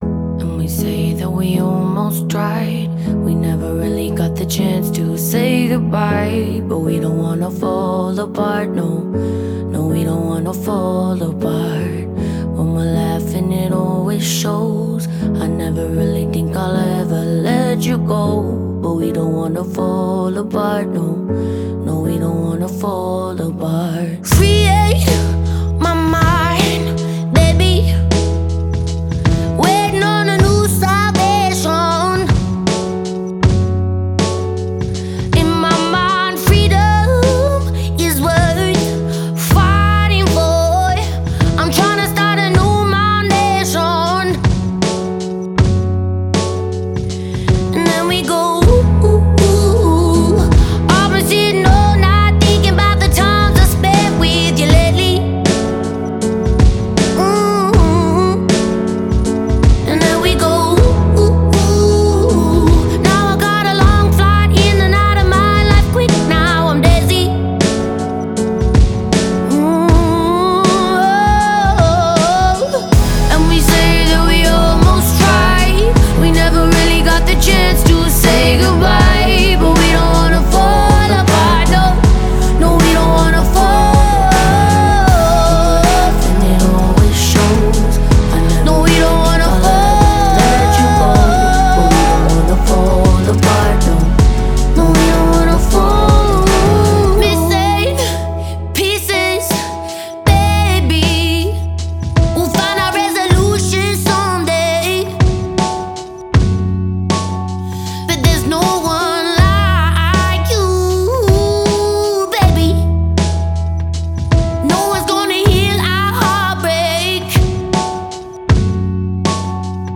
выполненный в жанре поп с элементами инди и электроники.